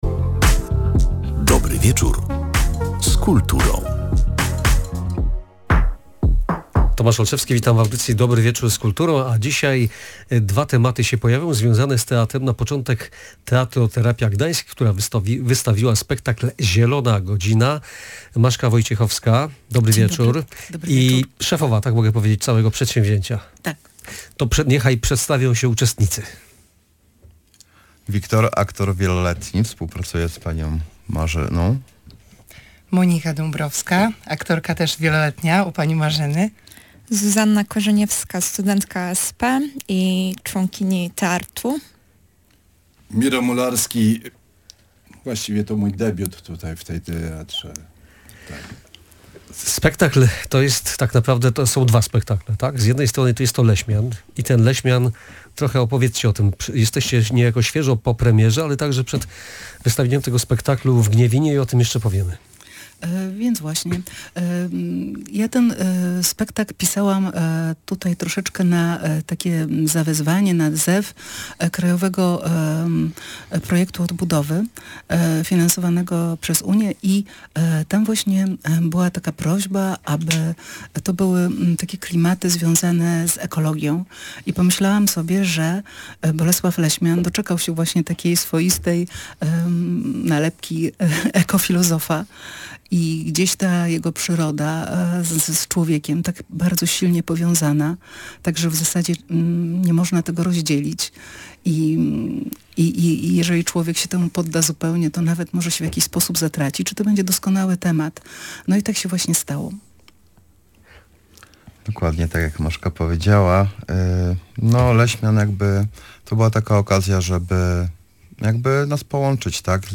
Teatralna audycja „Dobry Wieczór z Kulturą”